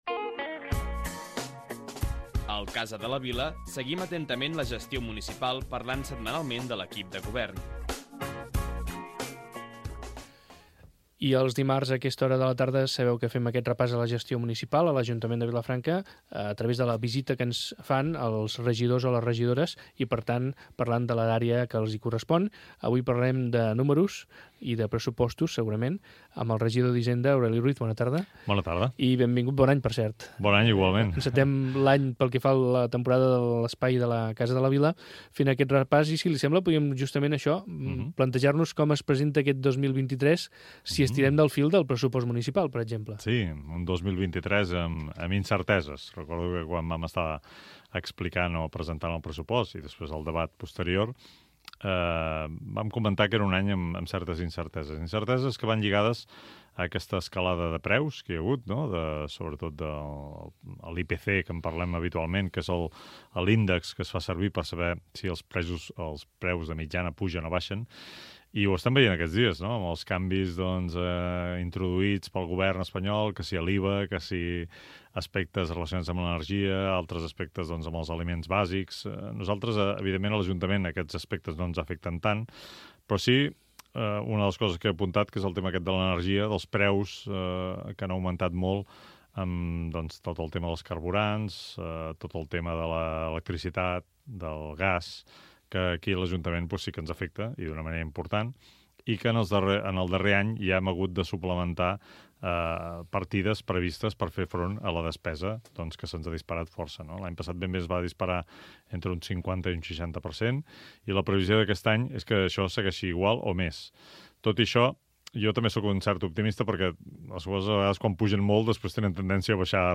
Careta del programa i entrevista al regidor Aureli Ruiz de l'Ajuntament de Vilafranca del Penedès sobre temes econòmics
Informatiu